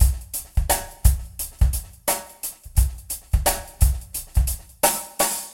描述：8 bars of the Roseanna beat, with some EQ and compression.